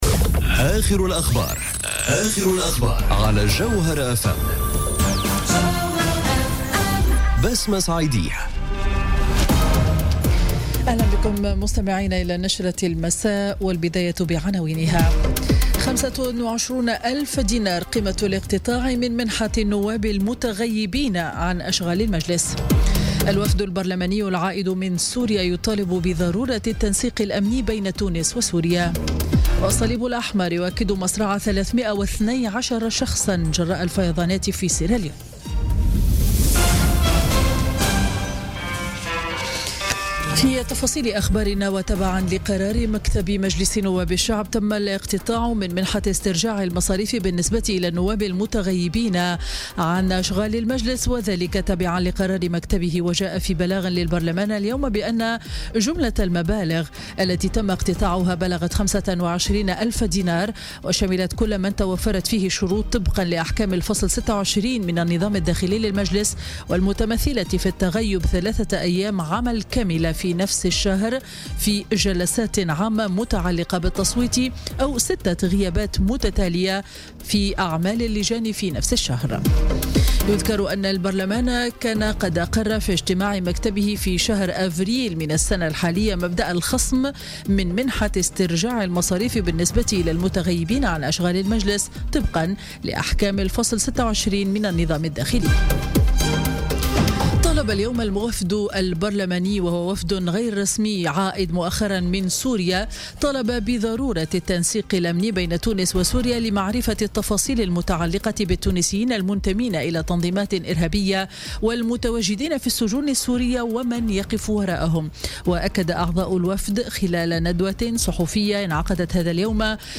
نشرة أخبار السابعة مساء ليوم الاثنين 14 أوت 2017